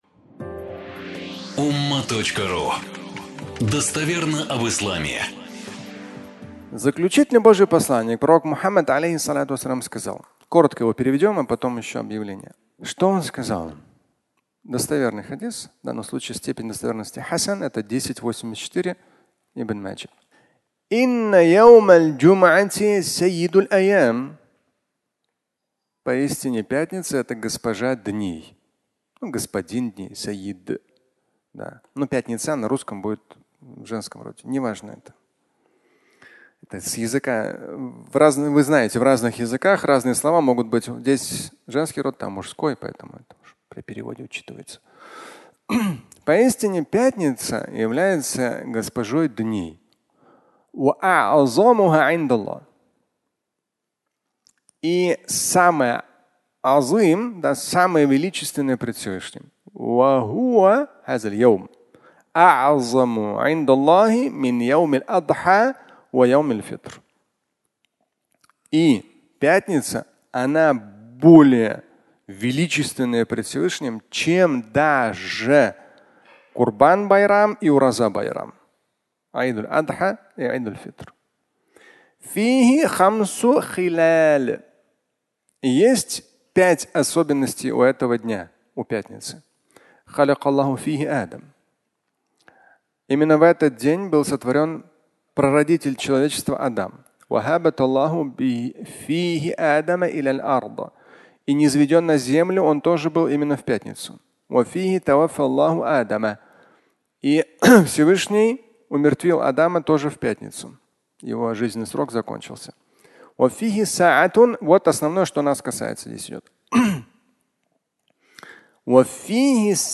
Пятница (аудиолекция)